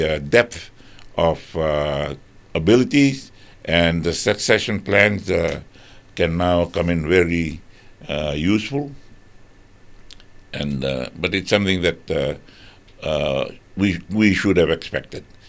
Prime Minister Sitiveni Rabuka has acknowledged the challenges faced by the coalition government during an interview on Radio Fiji One’s “Na Noda Paraiminista” program.